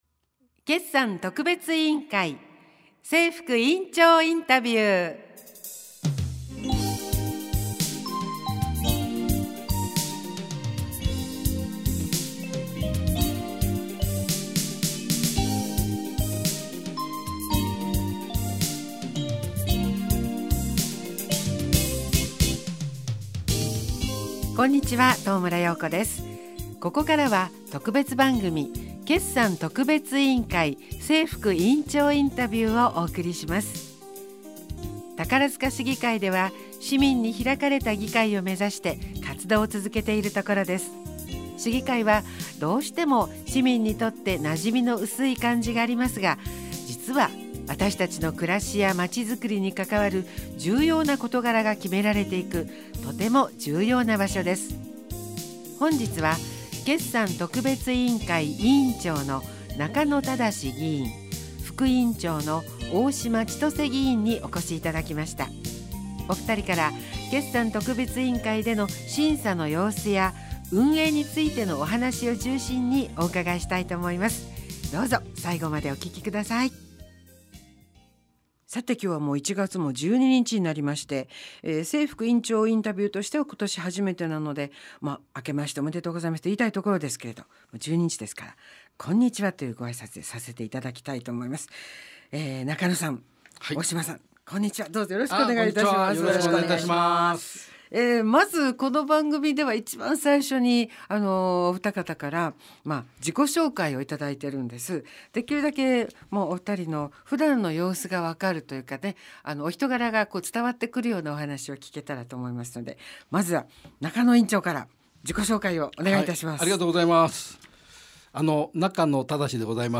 インタビュー放送
市議会議員が直接インタビューにお答えしています。